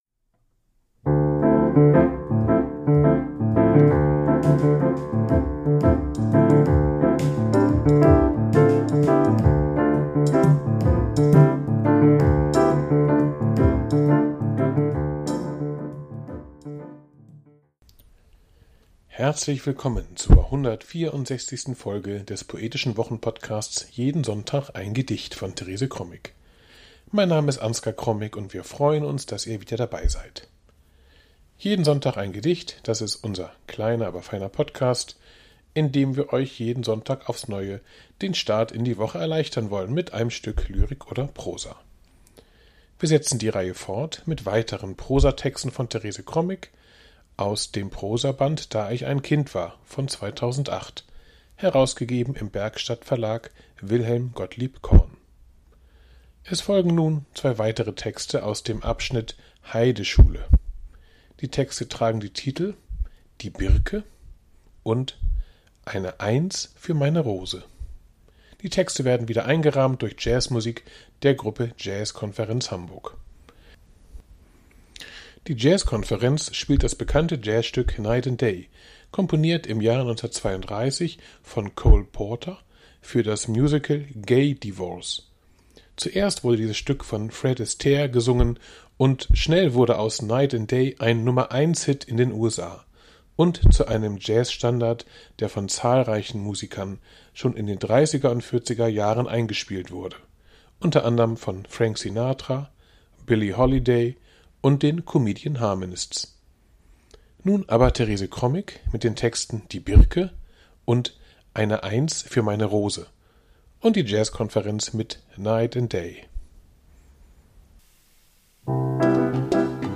Die Jazzkonferenz spielt das bekannte Jazz